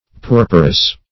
Puerperous \Pu*er"per*ous\, a.
puerperous.mp3